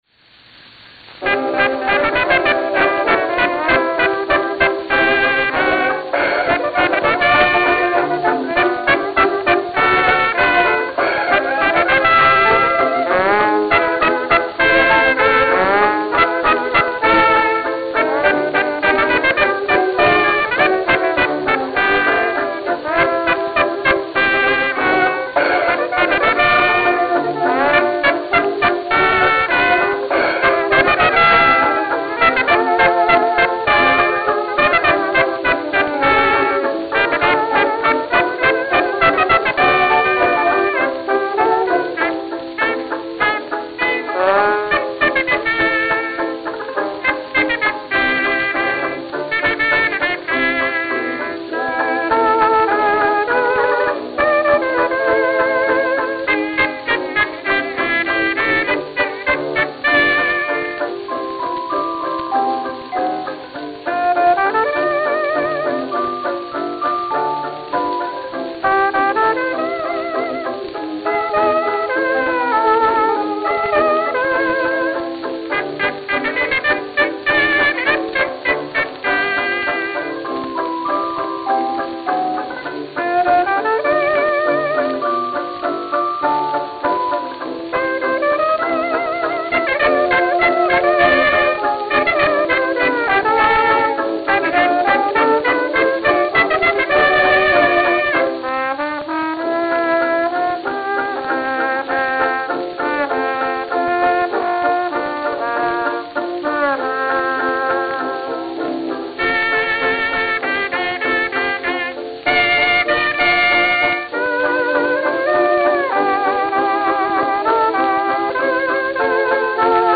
Long Island City, New York Long Island City, New York